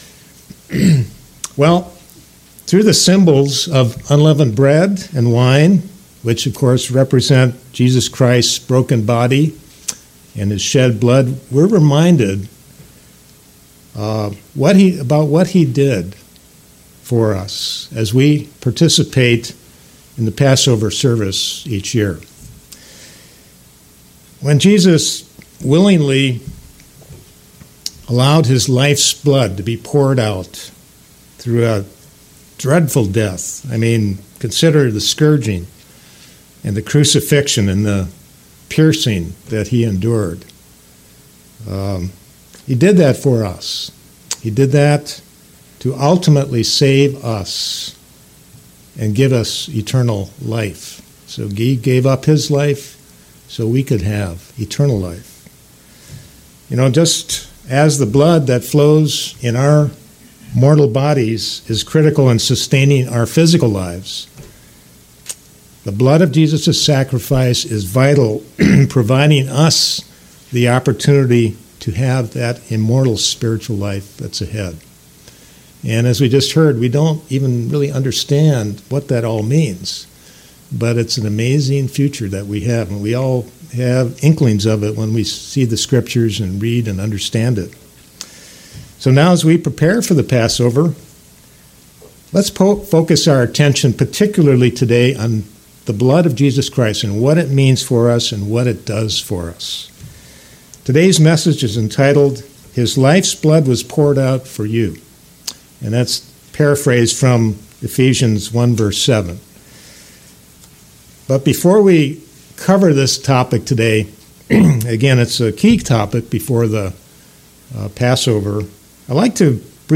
Sermons
Given in Knoxville, TN